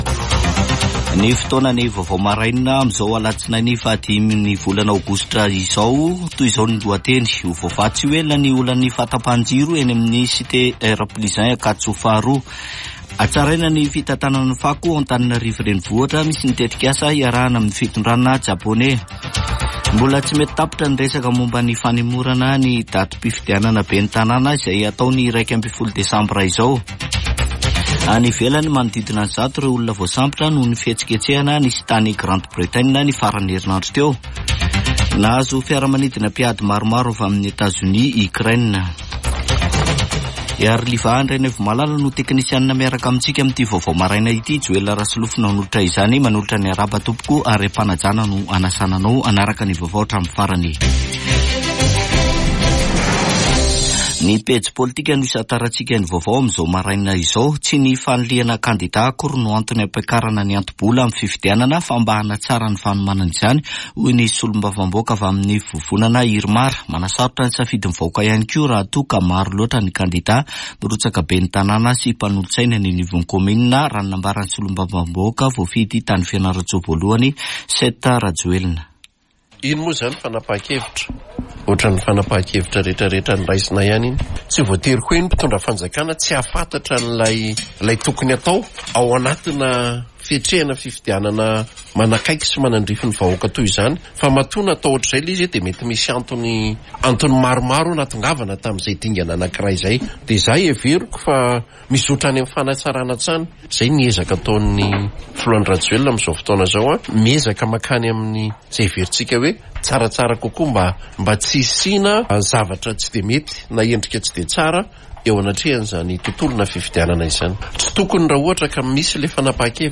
[Vaovao maraina] Alatsinainy 5 aogositra 2024